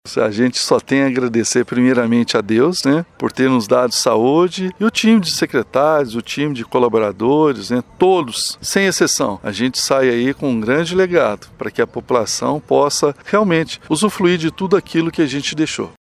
O atual prefeito, que fecha seu mandato com essa apresentação, agradeceu pelos 8 anos à frente do executivo municipal e disse estar deixando um legado em prol dos paraminenses: